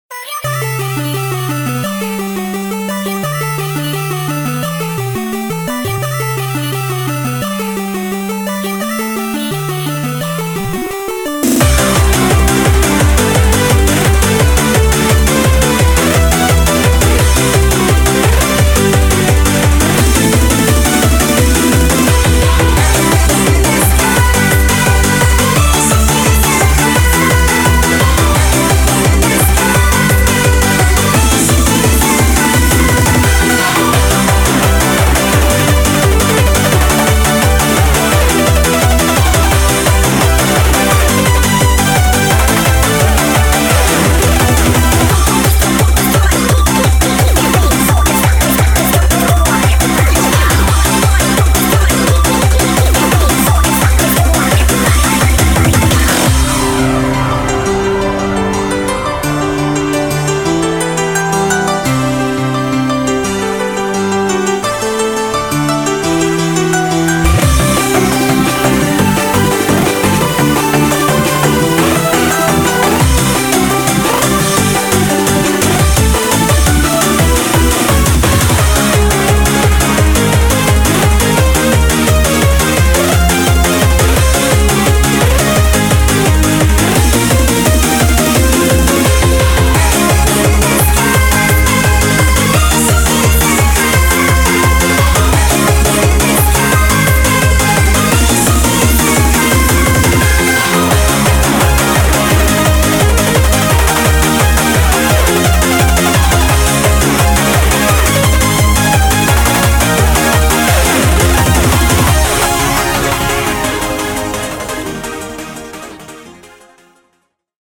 BPM172
Audio QualityPerfect (High Quality)
A fun eurobeat remix